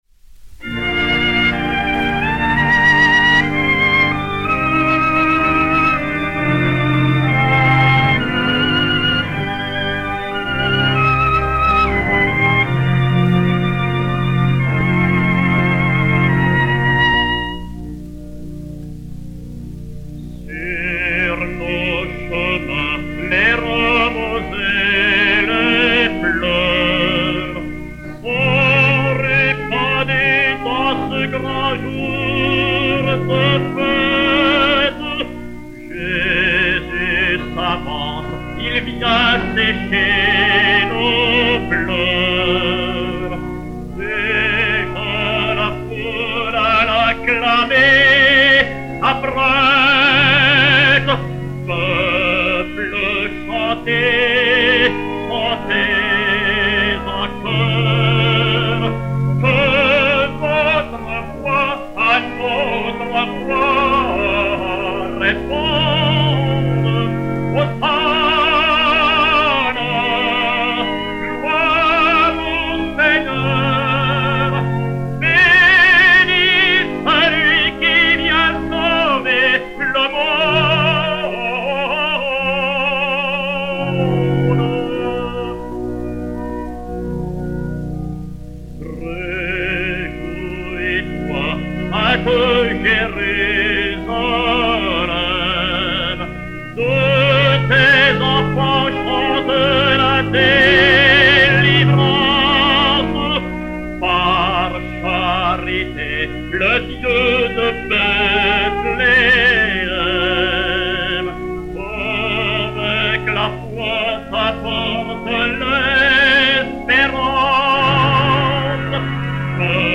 Hymne, poésie de Jules BERTRAND, musique de Jean-Baptiste FAURE (1864).
Violon, Violoncelle et Orgue